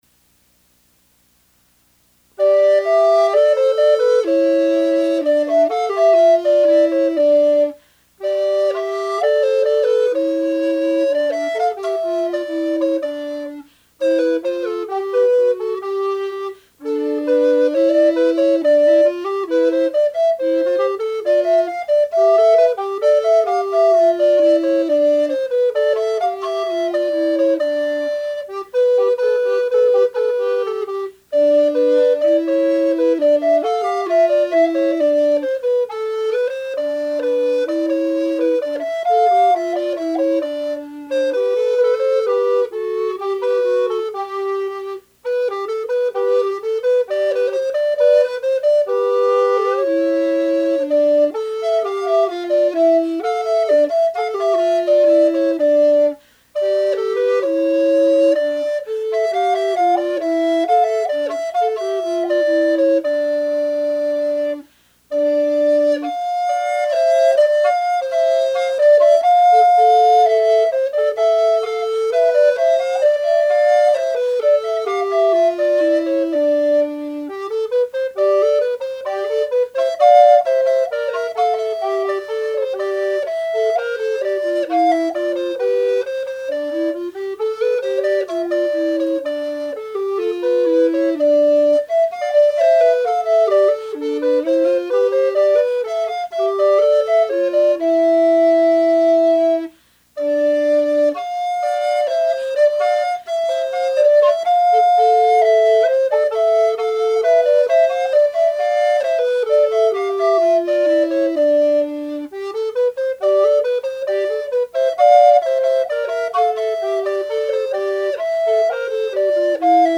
From the 5 November 2006 concert, La Caccia